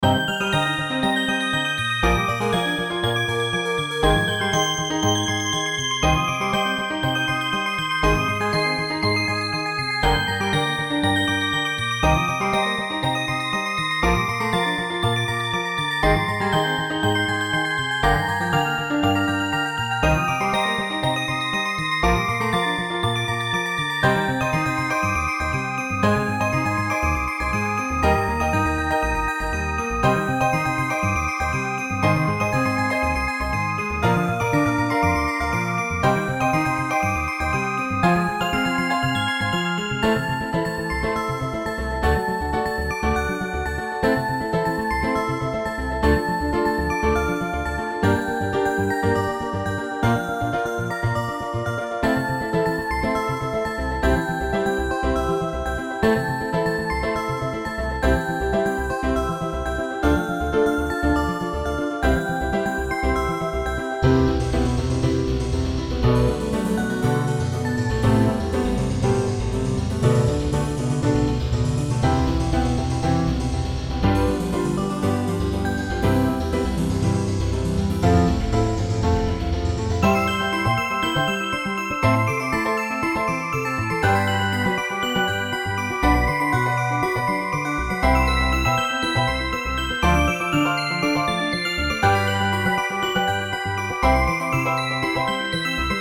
Fx5(ブライトネス)、ピアノ、アコースティックベース